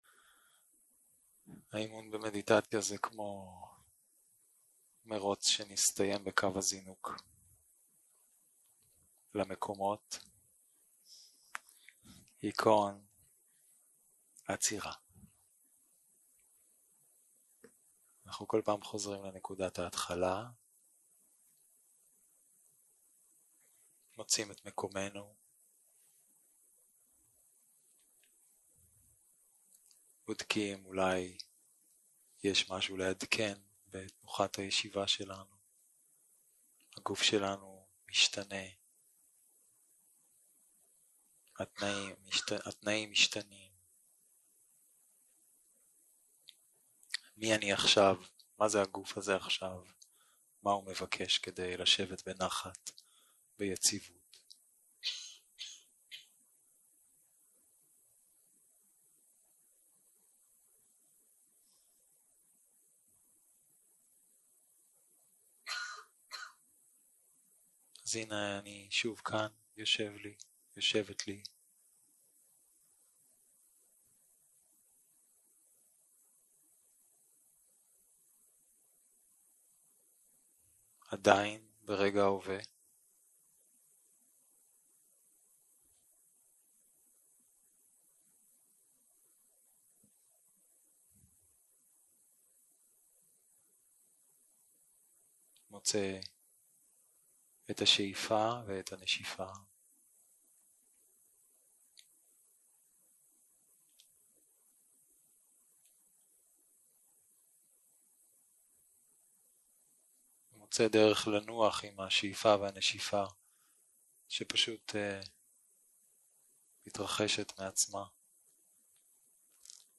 יום 5 - הקלטה 13 - צהרים - מדיטציה מונחית